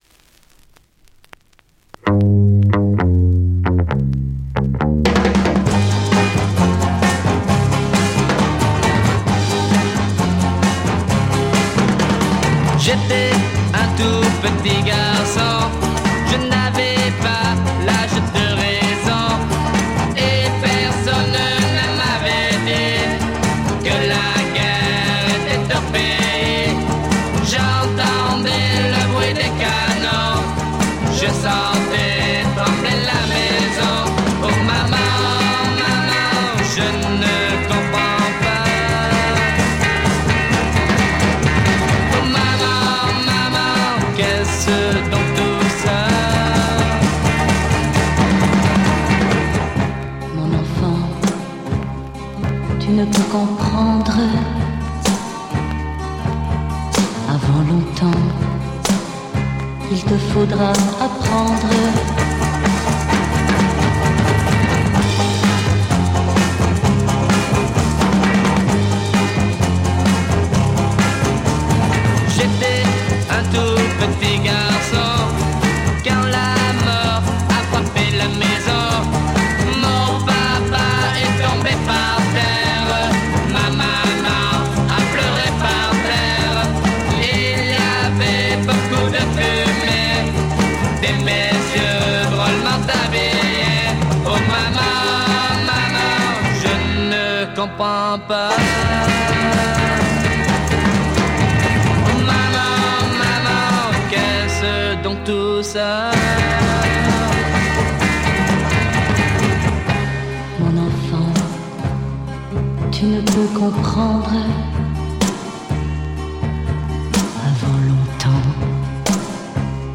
Play a decent garage folk punk sound!!